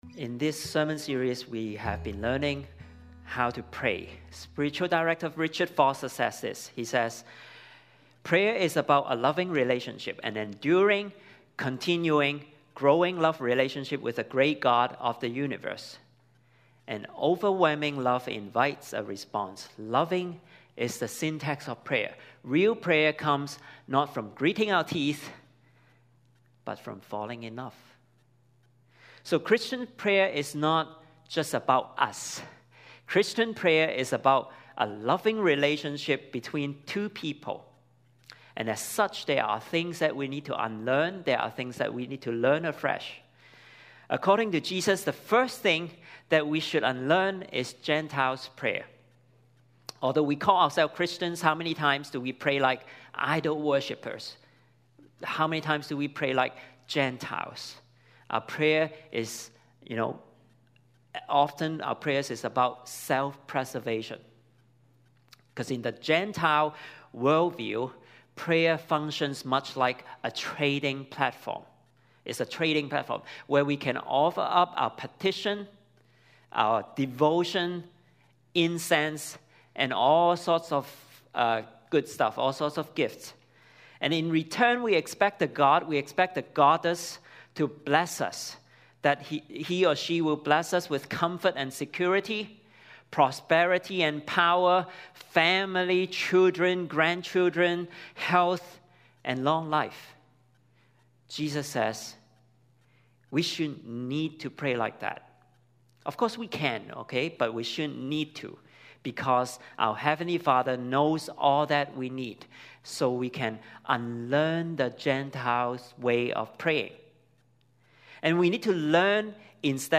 Sermons | Koinonia Evangelical Church | Live Different!